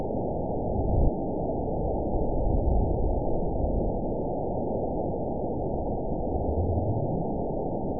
event 912608 date 03/30/22 time 10:47:51 GMT (3 years, 1 month ago) score 9.35 location TSS-AB04 detected by nrw target species NRW annotations +NRW Spectrogram: Frequency (kHz) vs. Time (s) audio not available .wav